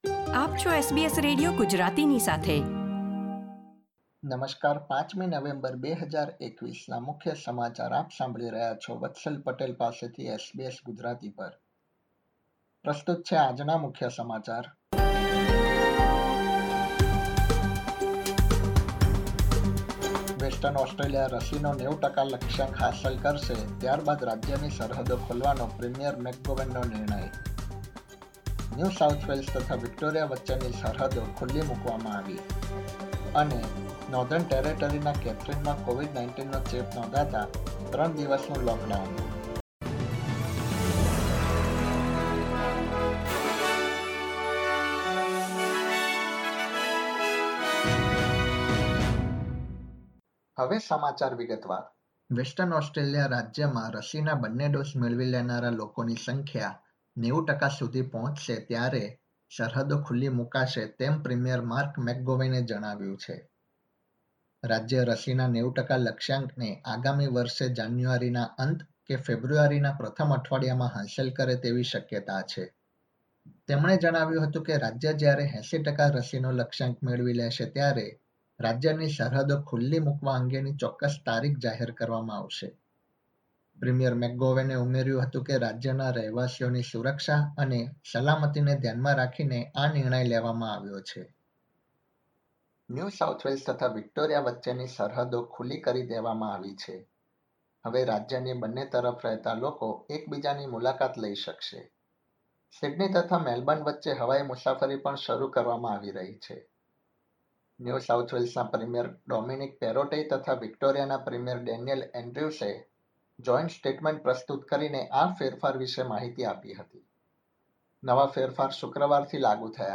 SBS Gujarati News Bulletin 5 November 2021
gujarati_0511_newsbulletin_0.mp3